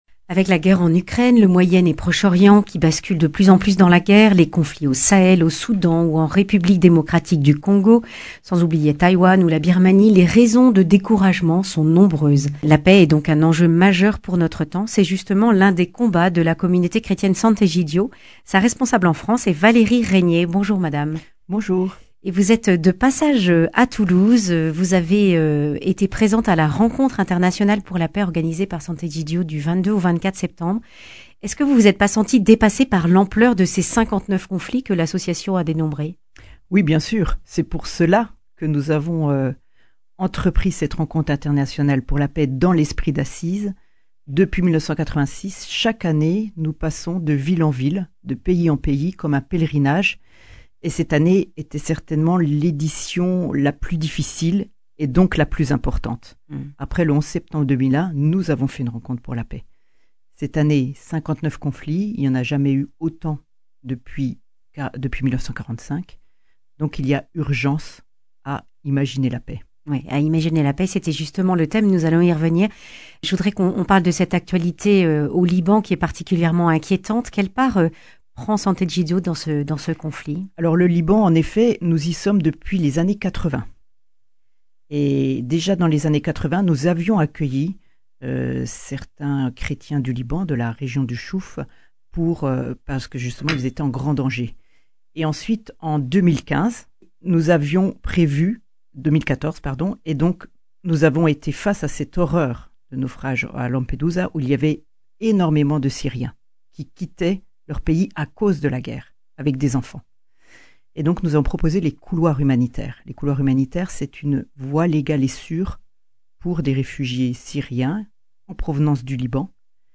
Accueil \ Emissions \ Information \ Régionale \ Le grand entretien \ Face à la multiplication des conflits dans le monde, la recherche de la paix (…)